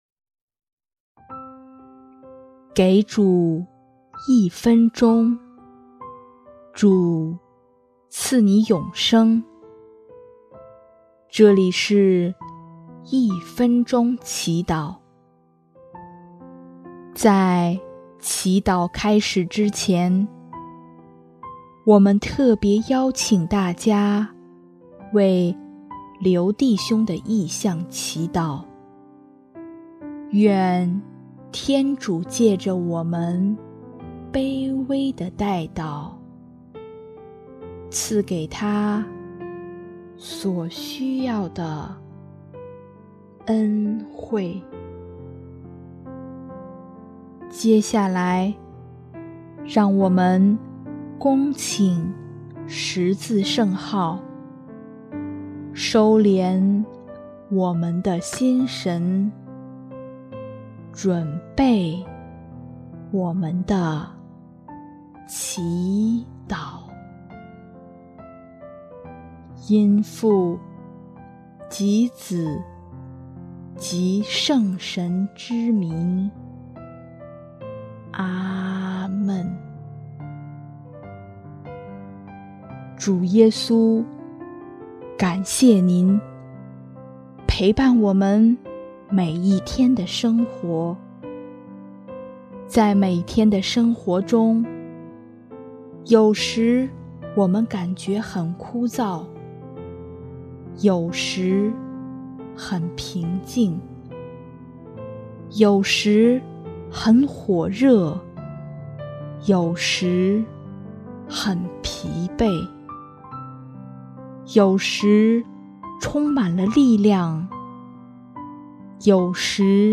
音乐 ：第一届华语圣歌大赛参赛歌曲《圣母妈妈在我心里》